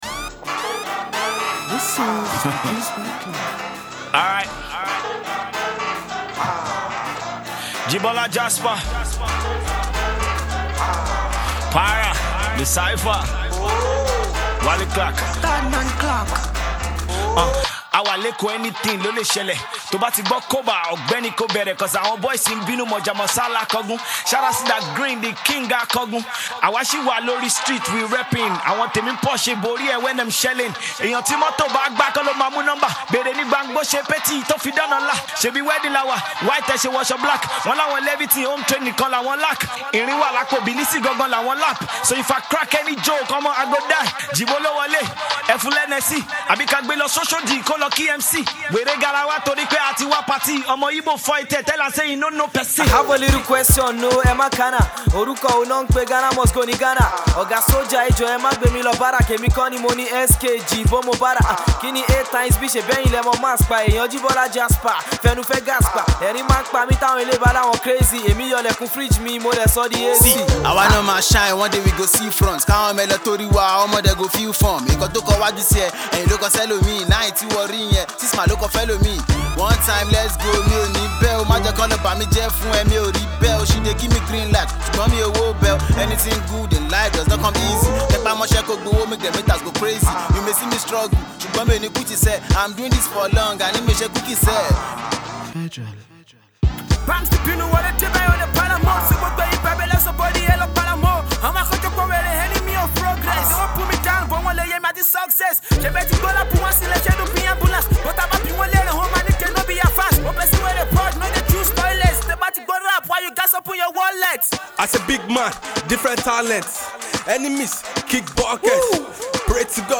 The young rappers were chosen from a number of artistes